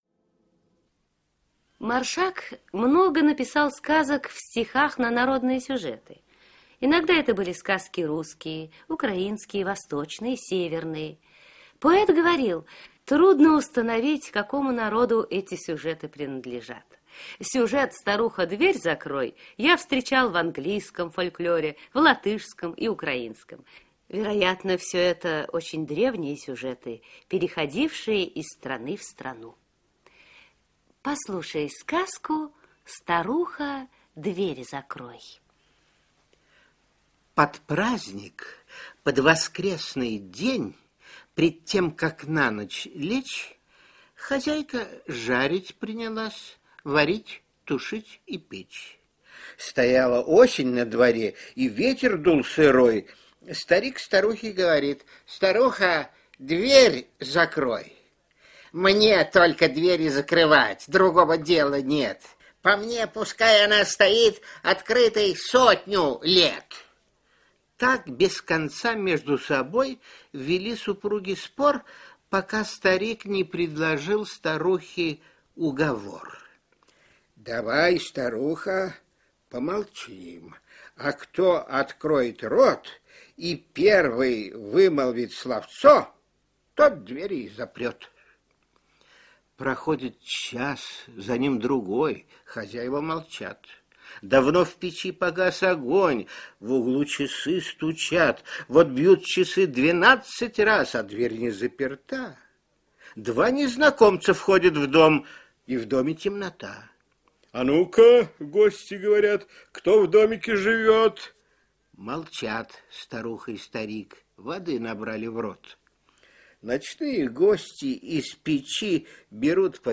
2. «Самуил Маршак – Веселое путешествие от “А” до “Я”. Радиокомпозиция. Часть 4» /
samuil-marshak-veseloe-puteshestvie-ot-a-do-ya-radiokompozitsiya-chast-4